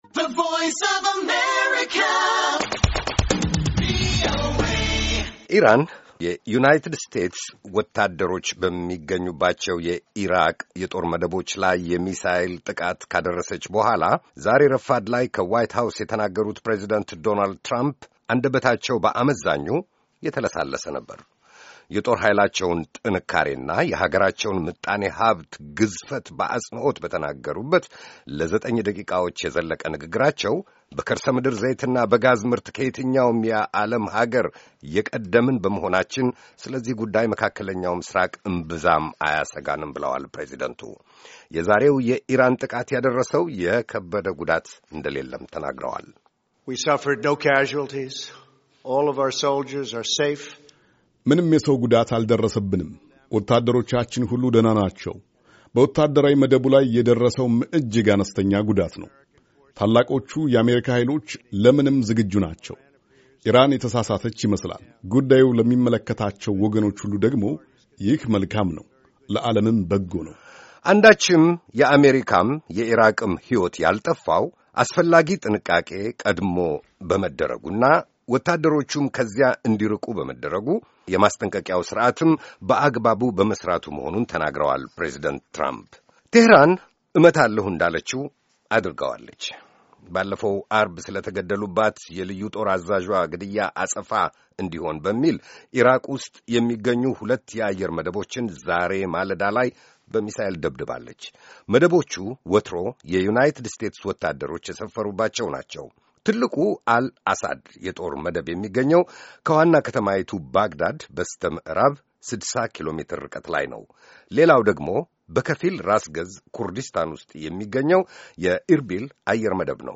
ኢራን የዩናይትድ ስቴትስ ወታደሮች በሚገኙባቸው የኢራቅ የጦር መደቦች ላይ የሚሳየል ጥቃት ካደረሰች በኋላ ዛሬ ረፋድ ላይ ከዋይት ሃውስ የተናገሩት ፕሬዚዳንት ዶናልድ ትረምፕ አንደበታቸው በአመዛኙ የተለሳለሰ ነበር።